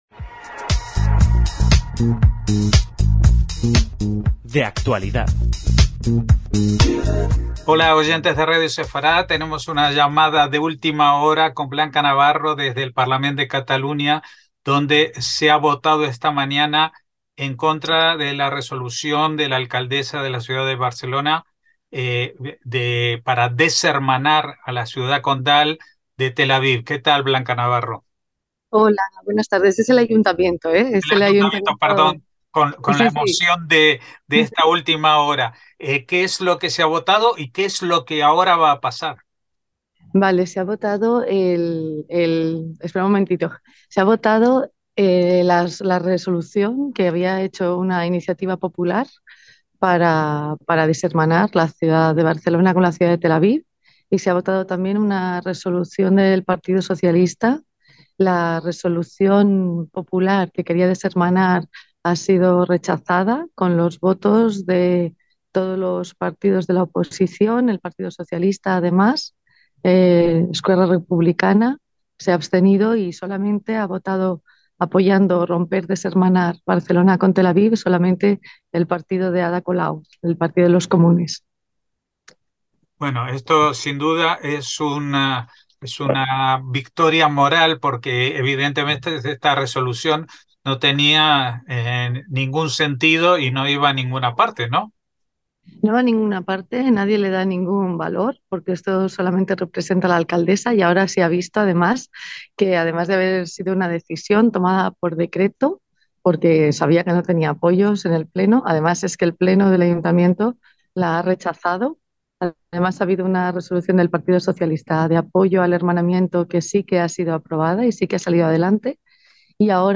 En una entrevista de muy última hora antes de la entrada del shabat
desde el Salón de Plenos del Ayuntamiento de Barcelona